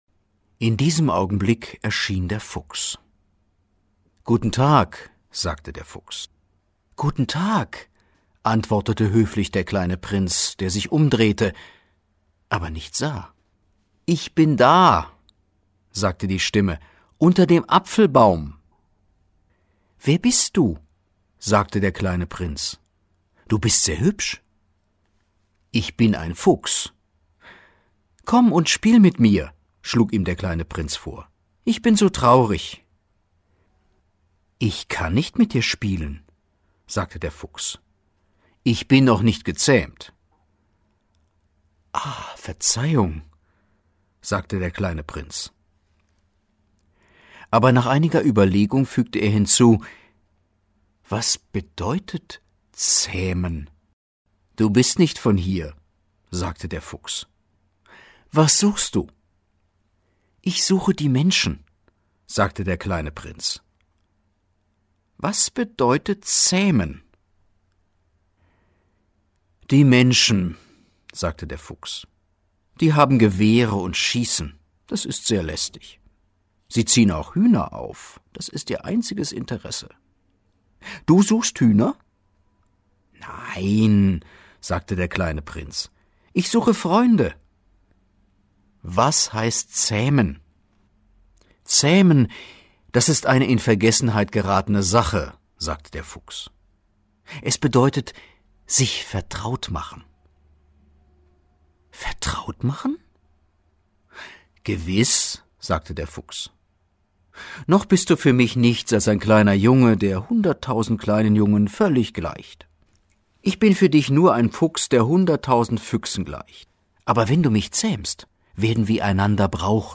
deutscher Profi-Sprecher. Breites Spektrum von sachlich bis ausgeflippt (Trickstimme).
Sprechprobe: Industrie (Muttersprache):